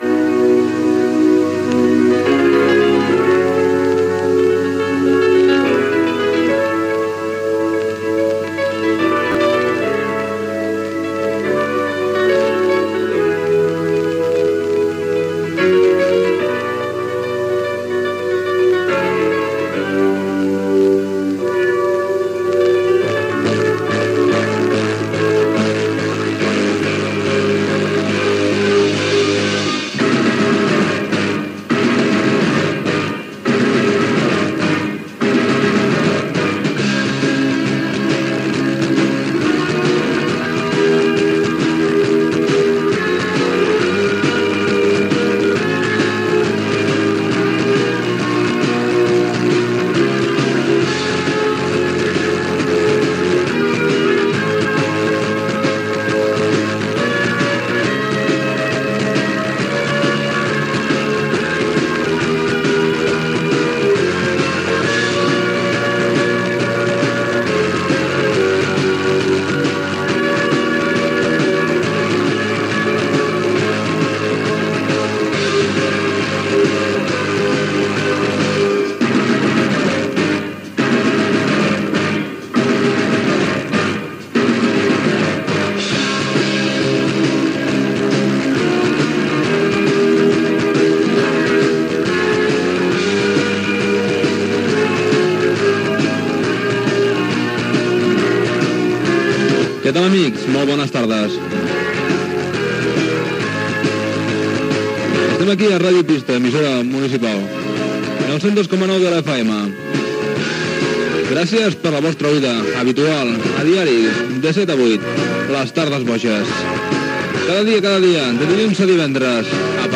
Tema musical, identificació i inici del programa
Musical